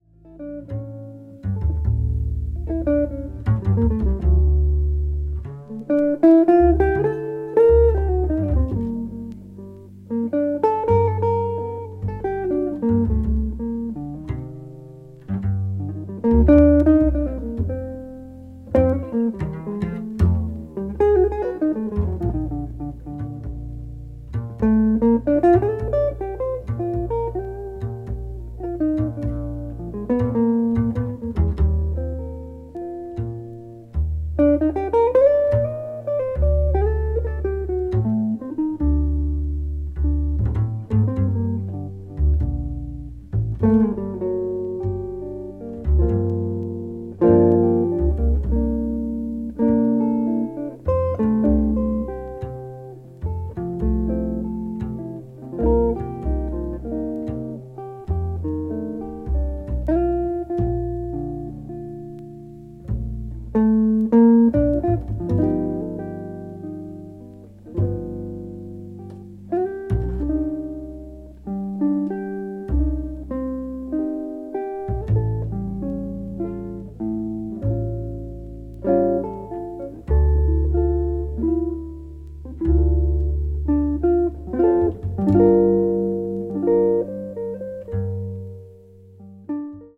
contemporary jazz   jazz standard   modern jazz